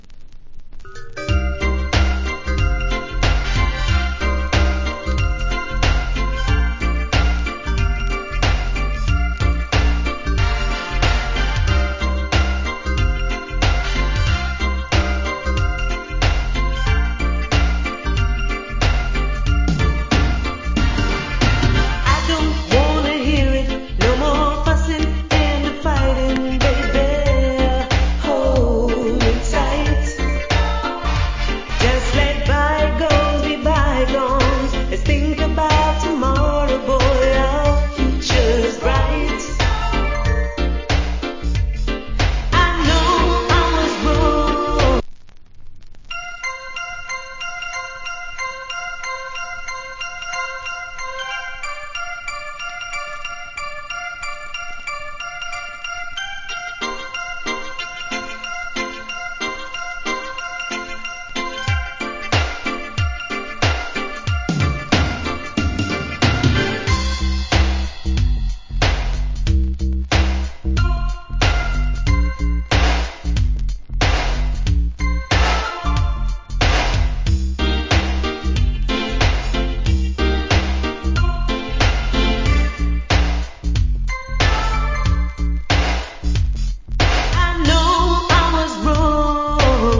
Nice Reggae Cover